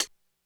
Index of /90_sSampleCDs/300 Drum Machines/Korg DSS-1/Drums01/04
HihatCl_127.wav